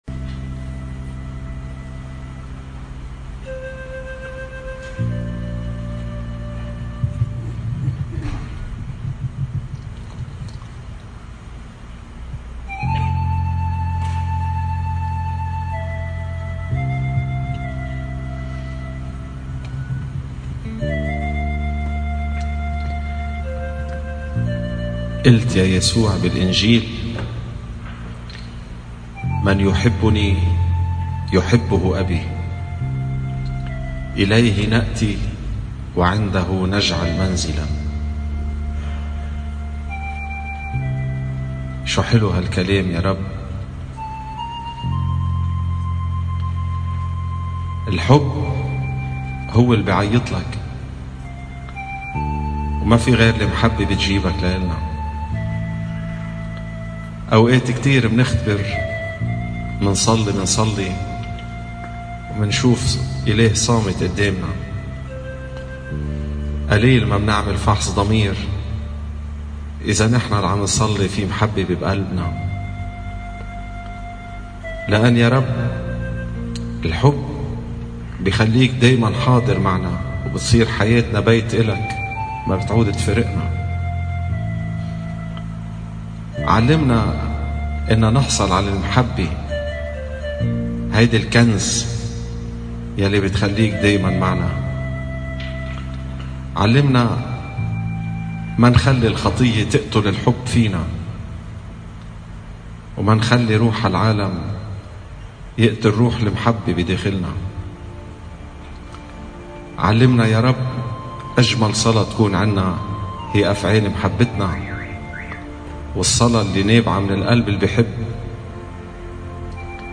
سجود أمام القربان المقدس